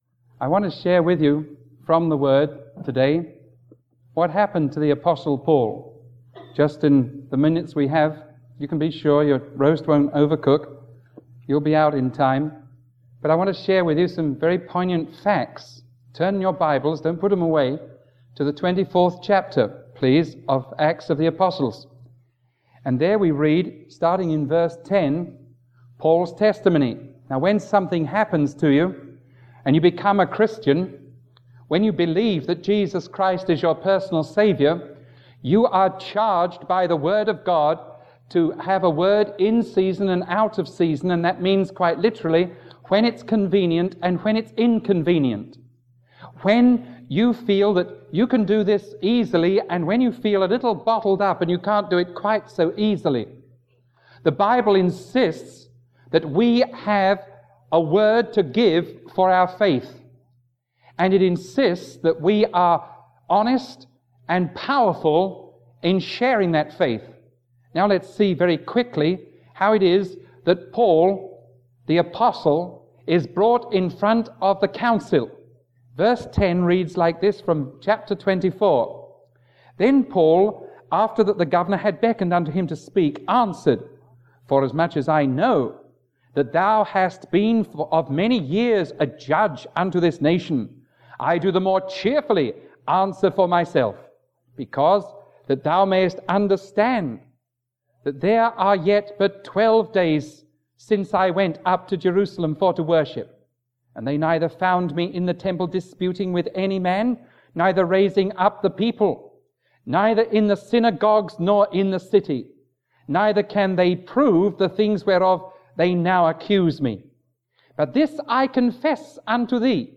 Sermon 0202A recorded on August 3, 1980 teaching from Acts 24:10-27; 26:1-32 – The Testimony of Paul.